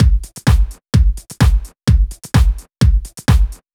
VMH1 Minimal Beats 06.wav